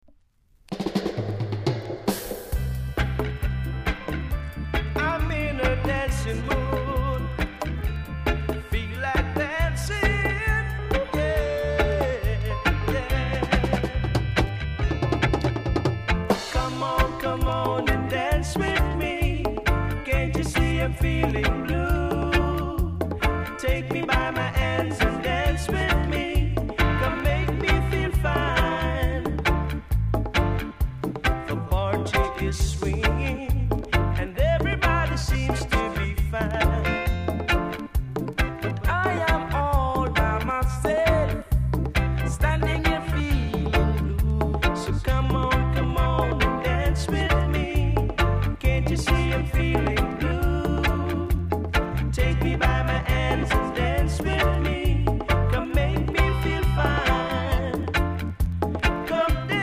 コメント ROOTS CLASSIC!!RARE JA PRESS!!※裏面クレジット間違い